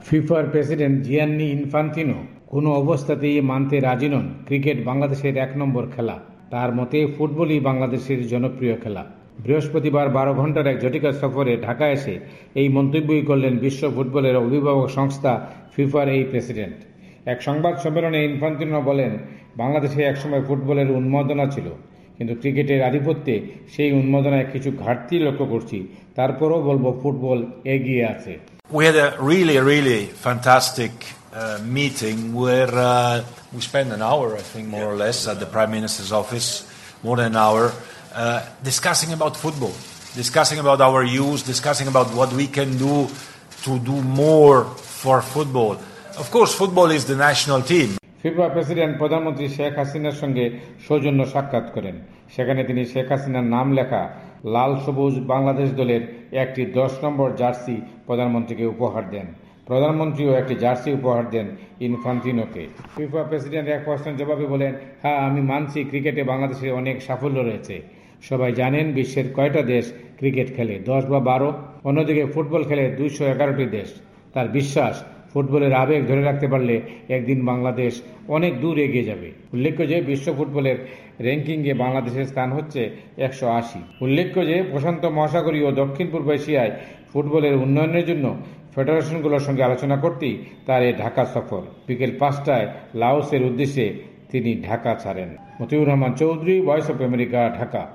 এক সংবাদ সম্মেলনে ইনফান্তিনো বলেন, বাংলাদেশে এক সময় ফুটবলের উন্মাদনা ছিল।